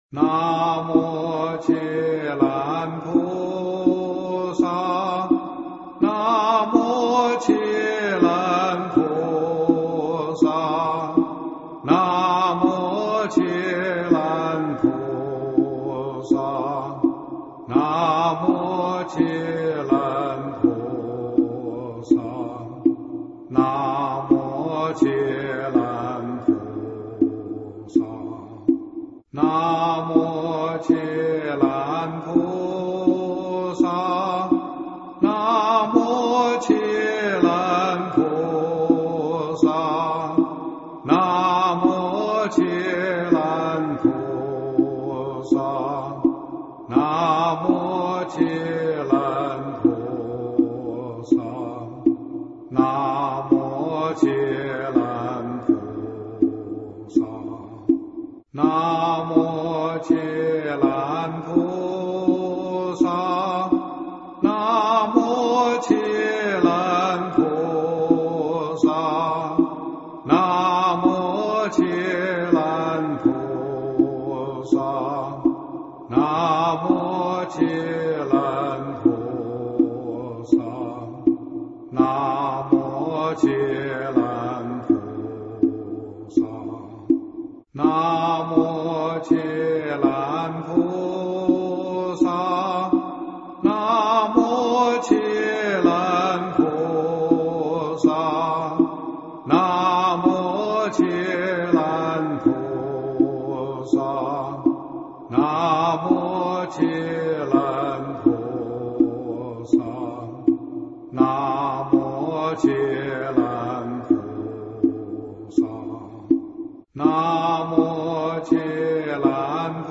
经忏
佛教音乐